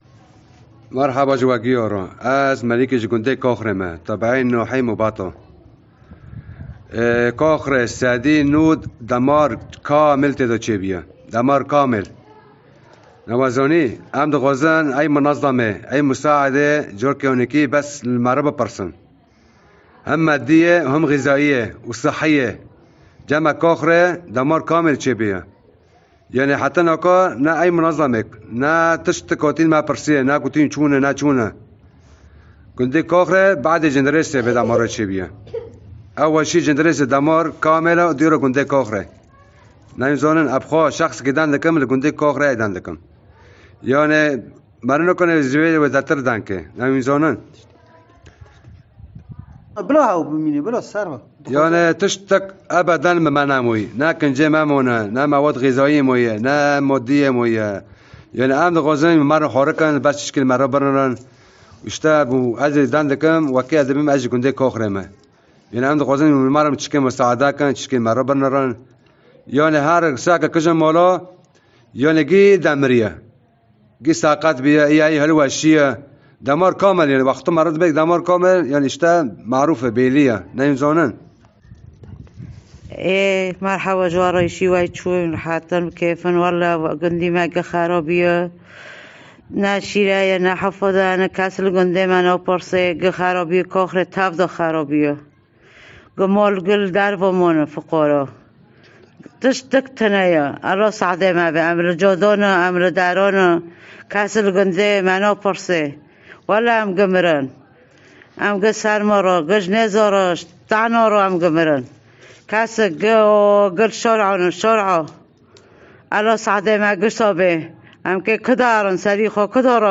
قصص صادمة في تسجيلات صوتية وفيديوهات حصرية أرسلها عدد من سكان قرية كاخري بريف عفرين إلى منصة تارجيت الإعلامية
مواطن آخر من قرية كاخري تحدث عن انهيار وسقوط عشرات البيوت والمنازل بسبب الزلزال المُدمر، كما لا يزال هناك عدد كبير من العائلات تحت الأنقاض، نتيجة انعدام المعدات والآليات الثقيلة والفرق المتخصصة، ليوجه نداء استغاثة إلى المنظمات والجهات الإنسانية لمد يد العون وتقديم المساعدات العاجلة في ظل هذه الكارثة، قائلاً: “نحن بحاجة إلى خيام لإيواء سكان القرية الناجين الذين يعيشون في الشوارع منذ 6 أيام”